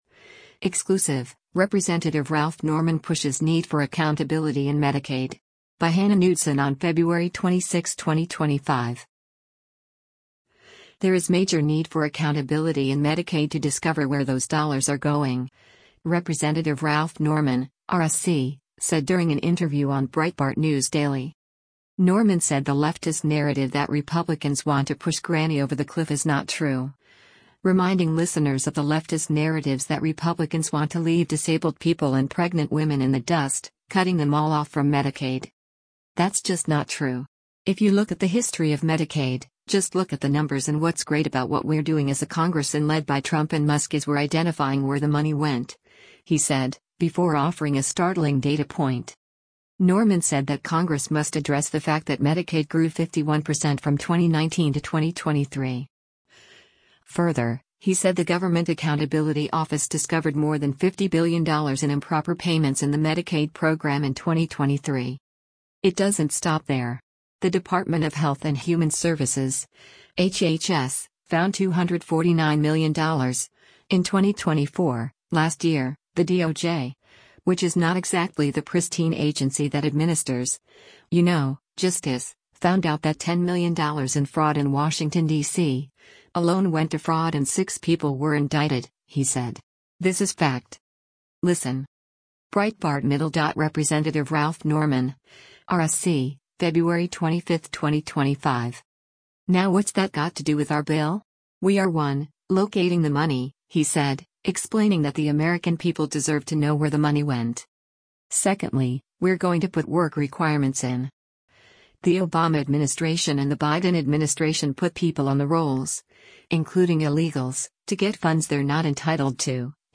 There is major need for accountability in Medicaid to discover where those dollars are going, Rep. Ralph Norman (R-SC) said during an interview on Breitbart News Daily.